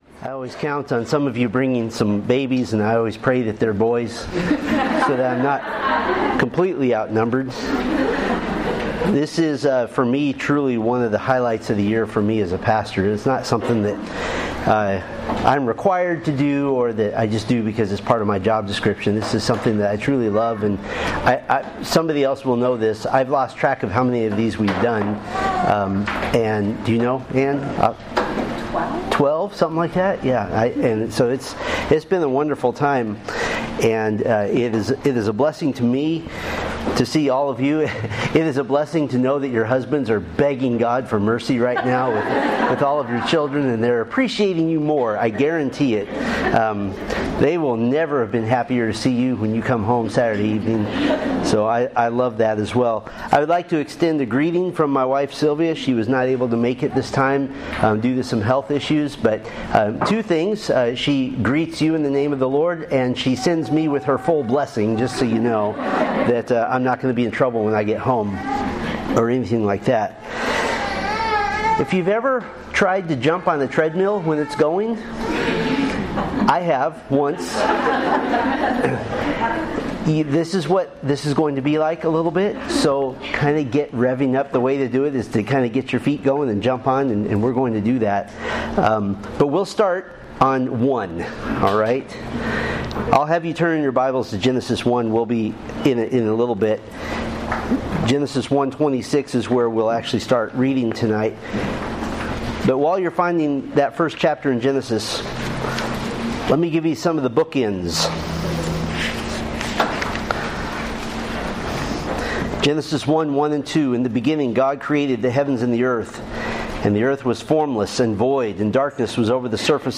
The Story of the Bible: From Eden to New Earth (Women's Retreat 2025)